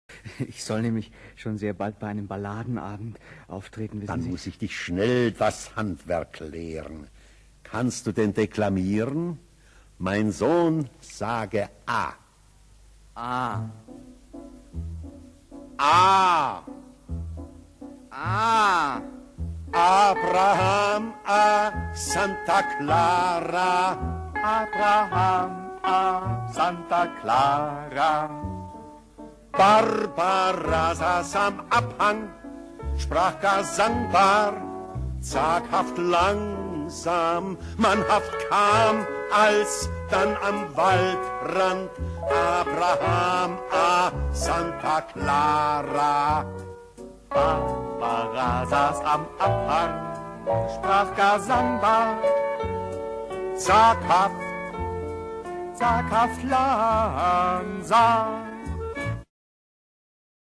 Rundfunkmusical (Hörspiel)